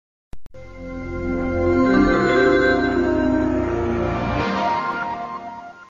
startup.mp3